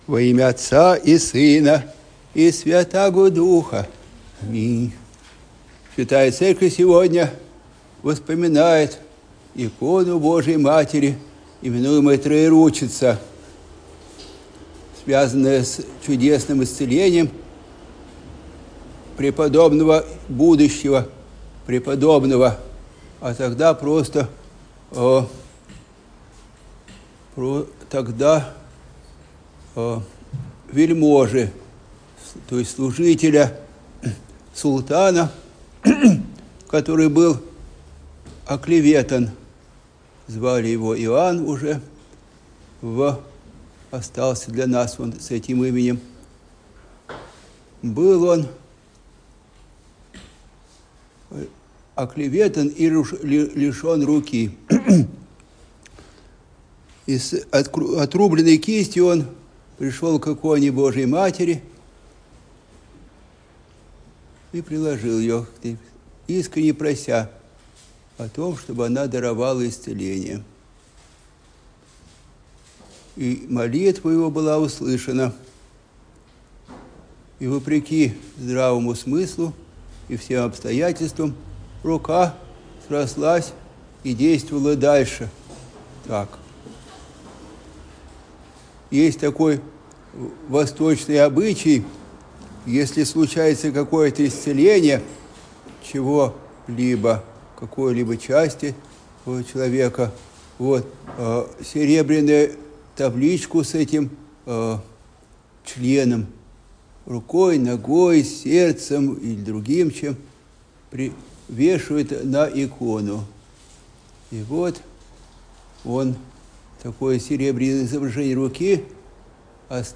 Аудиопроповеди